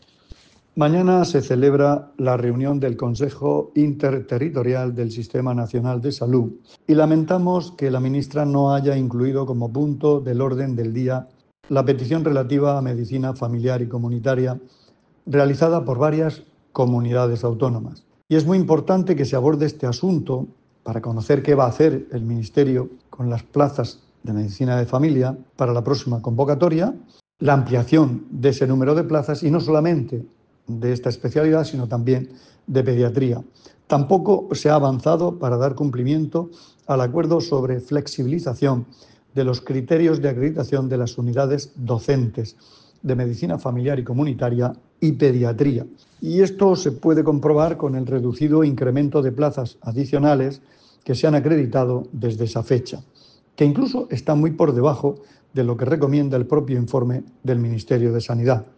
Sonido/ Declaraciones del consejero de Salud, Juan José Pedreño, sobre las plazas de Medicina de Familia y Pediatría.